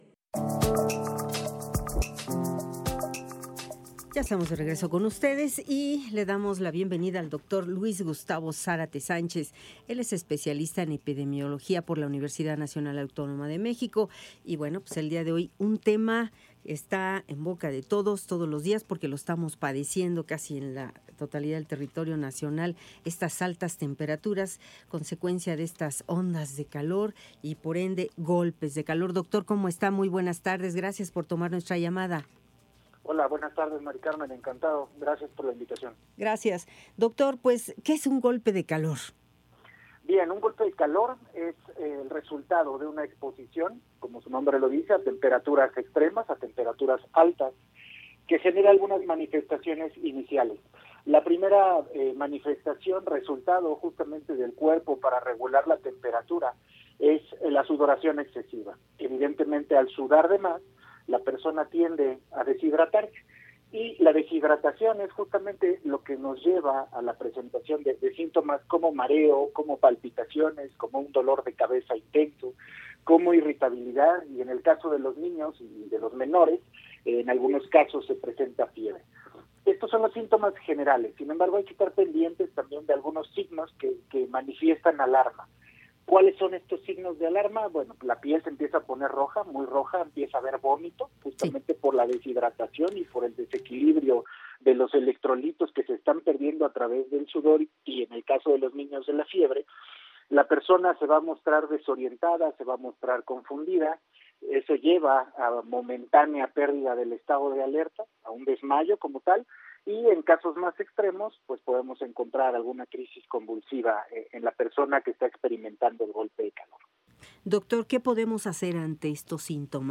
Temporada de calor terminará hasta octubre En entrevista para IMER Noticias